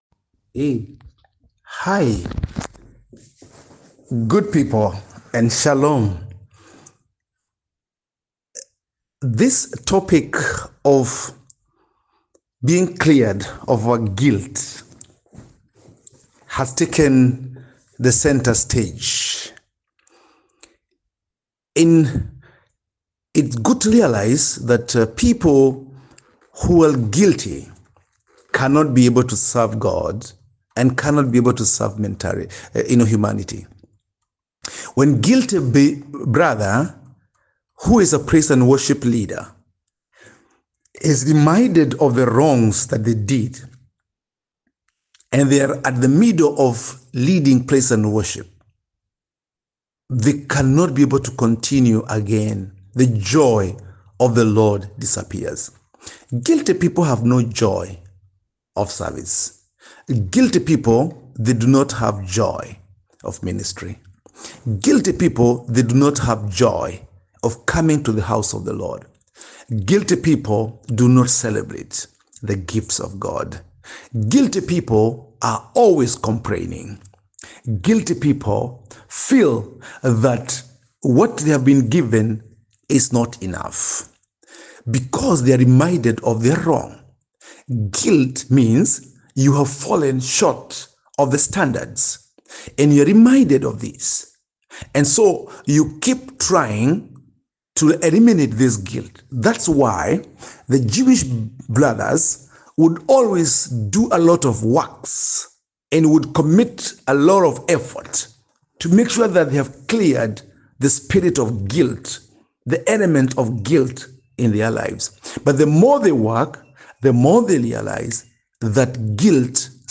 Post Lesson Teaching Summary Great job completing the study! Take a moment to listen to this summary to reinforce your group’s understanding of the text and ensure you’re all on the same page.